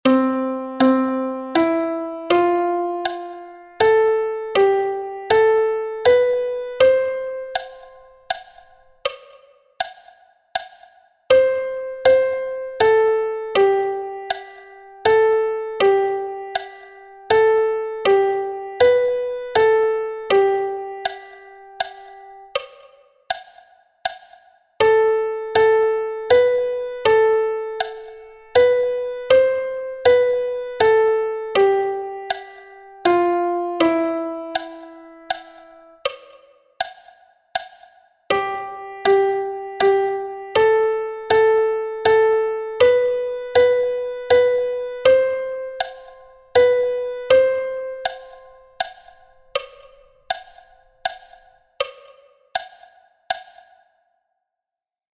It is a compound triple meter.
leccion0_ritmo_9_8_metronomo.mp3